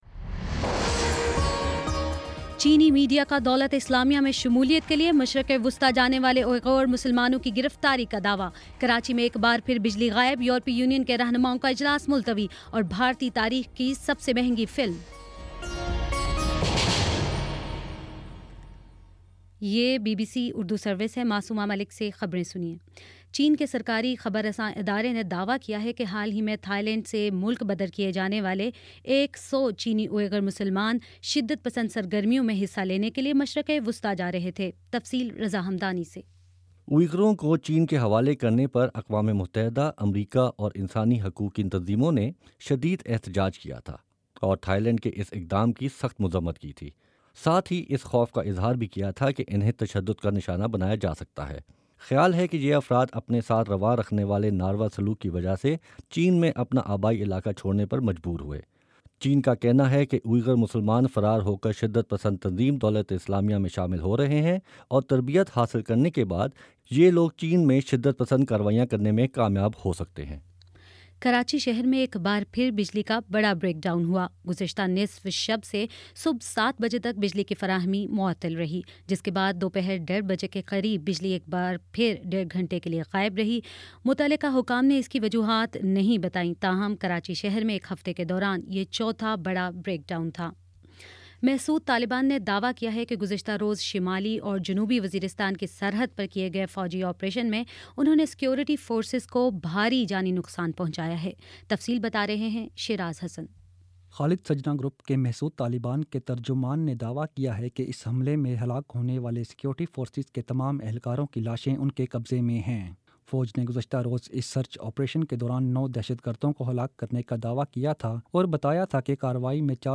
جولائی 12: شام پانچ بجے کا نیوز بُلیٹن